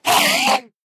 scream3.mp3